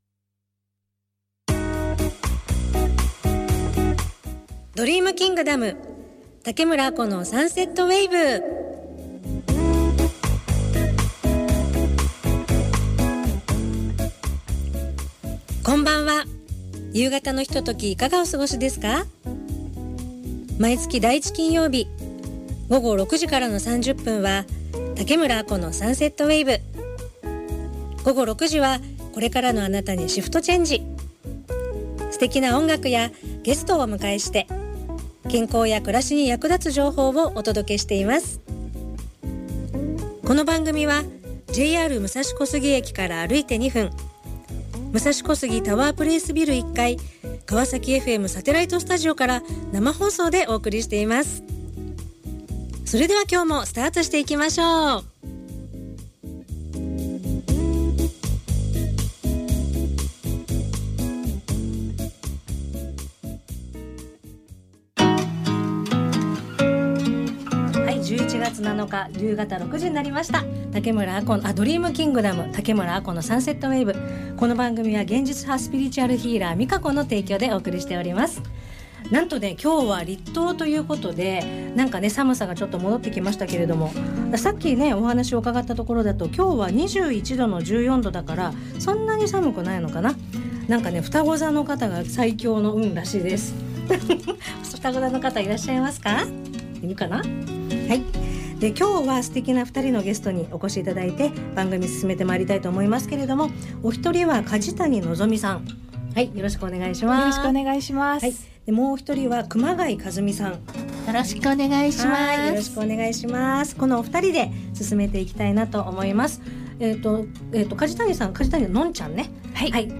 かわさきFMサテライトスタジオから生放送